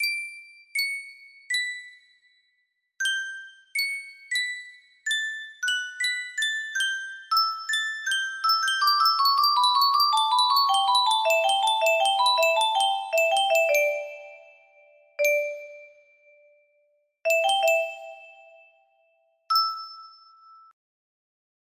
Chime music box melody
Full range 60